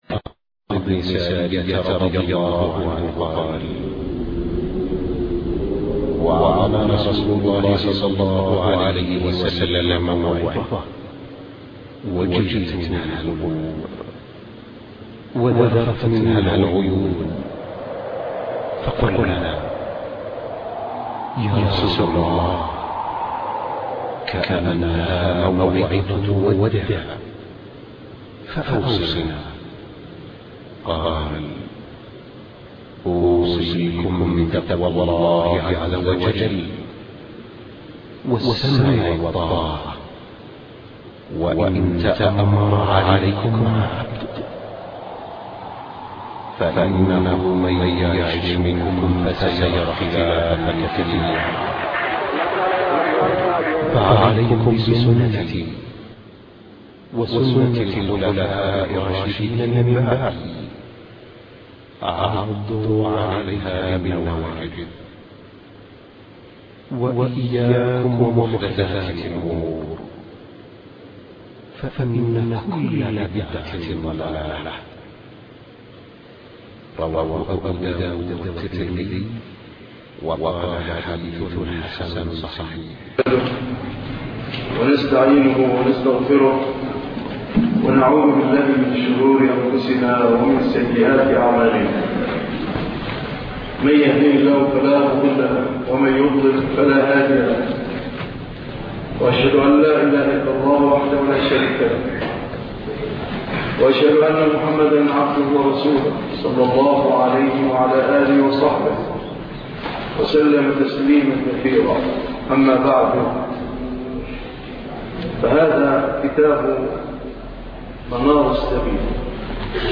الدرس 17 ( شرح منار السبيل )